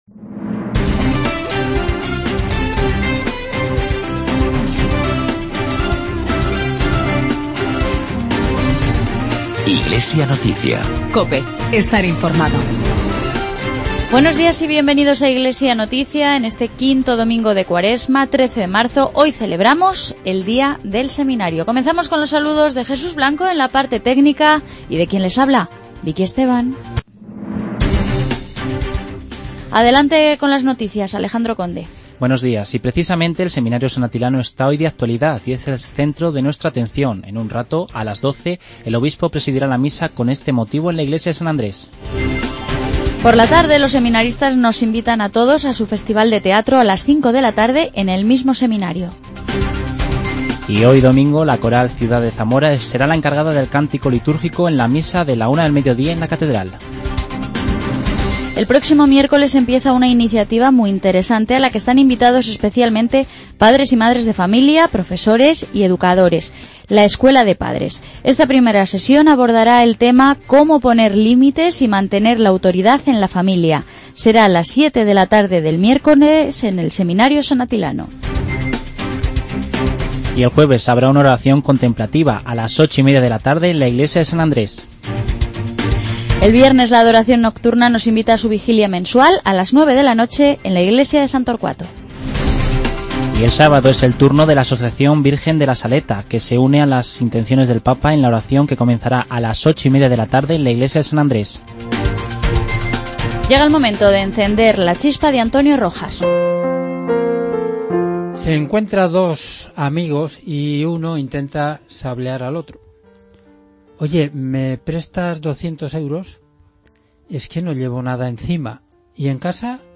Informativo diocesano.